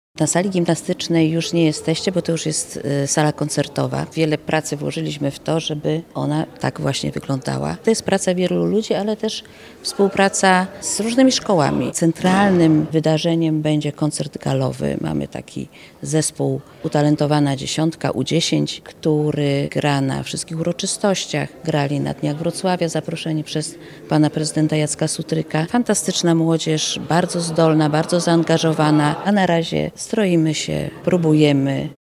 Hala sportowa zamienia się w estradę, a na korytarzach panuje radosny gwar.